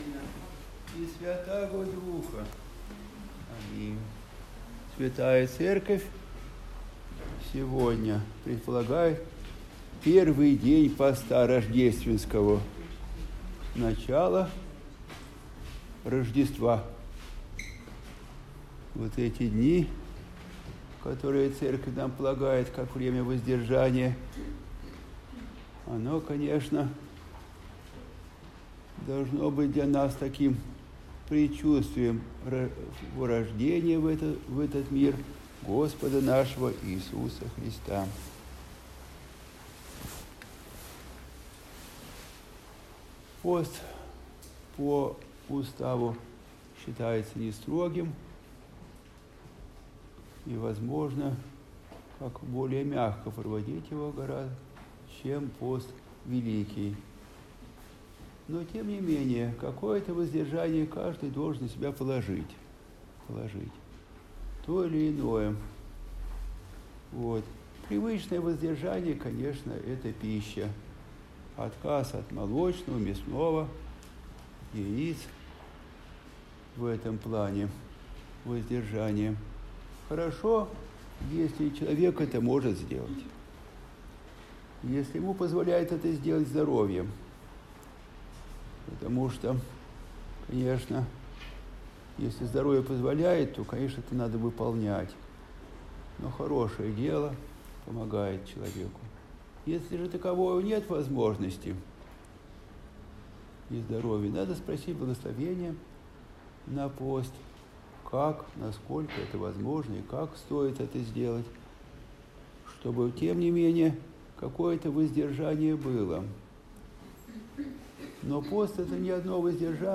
Проповедь прот.